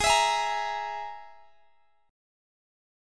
Absus2#5 Chord
Listen to Absus2#5 strummed